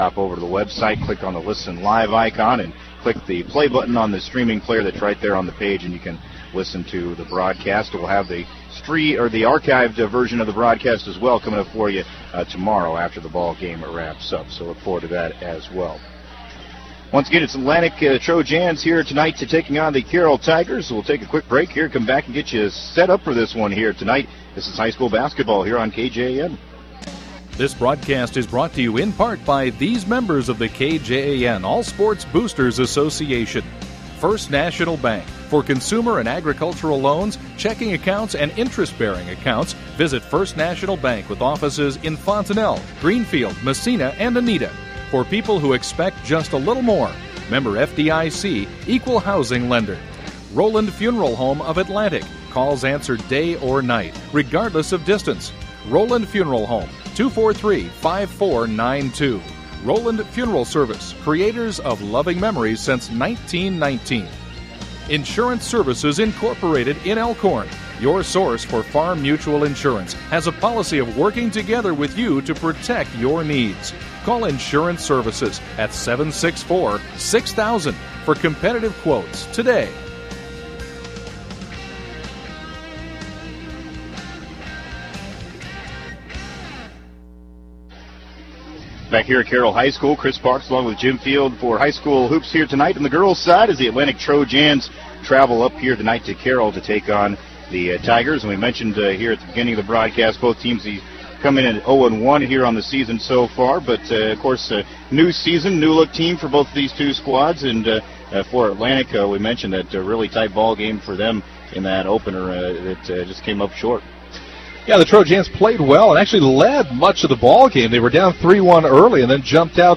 have the call from the game played Monday, November 28th.